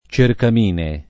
cercamine [ © erkam & ne ] s. m.; inv.